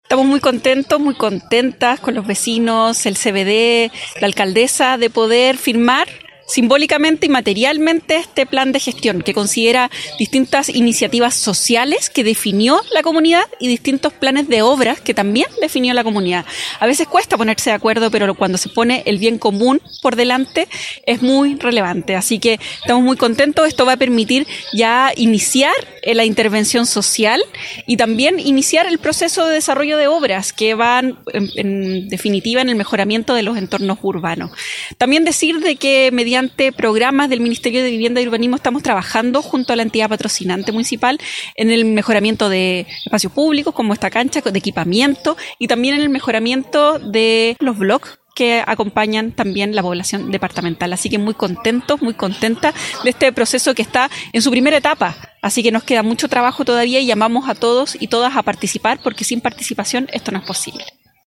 La Seremi de Vivienda y Urbanismo de la región de Valparaíso, Belén Paredes, valoró la alta participación vecinal para concretar cada una de las iniciativas del «Quiero Mi Barrio».